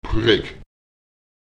Lautsprecher prek [prEk] tot